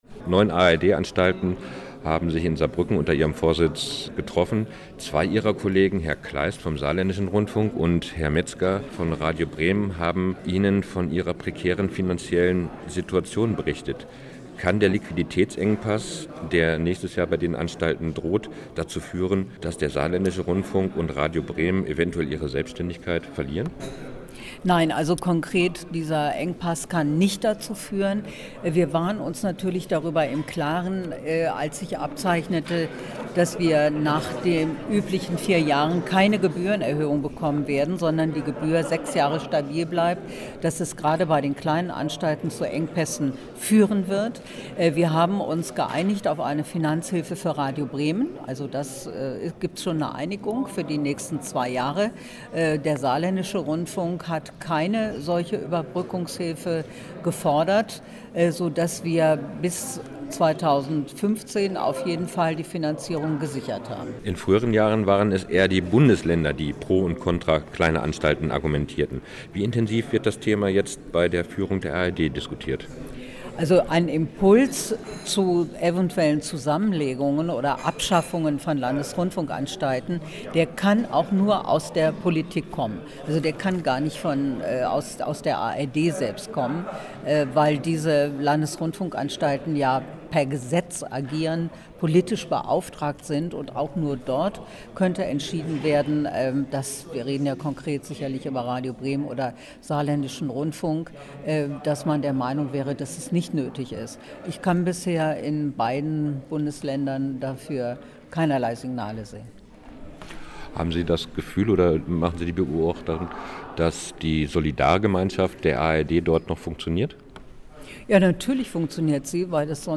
* Monika Piel, WDR-Intendantin, ARD-Vorsitzende (2011-2012)
Was: Interview über den ARD-Finanzausgleich